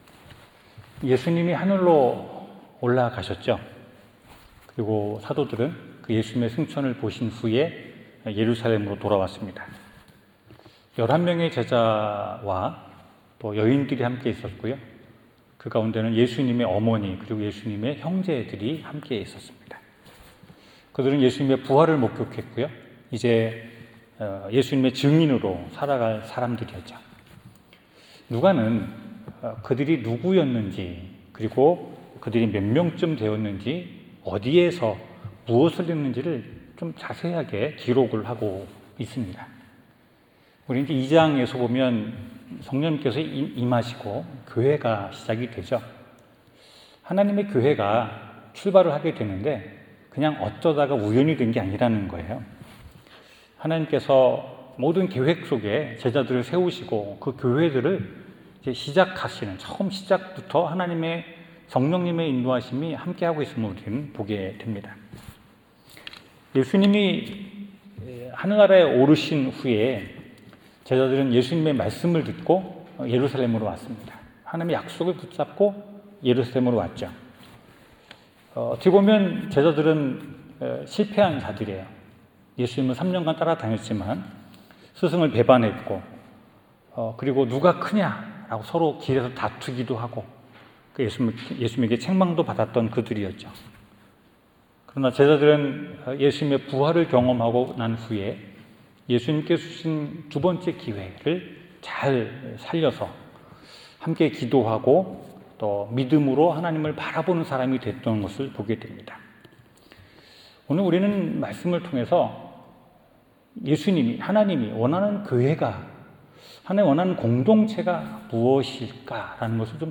사도행전 1:12-26절 설교